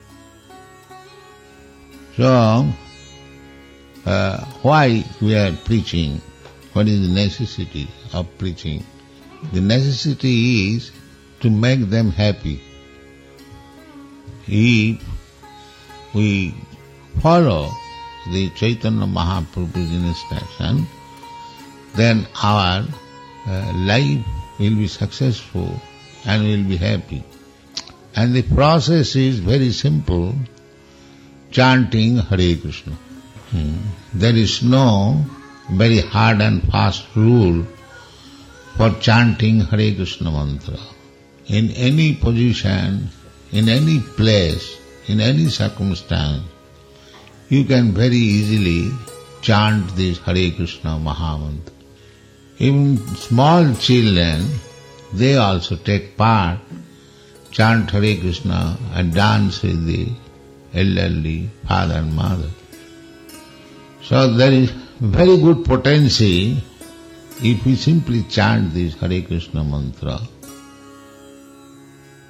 (730417 – Lecture SB 01.08.25 – Los Angeles)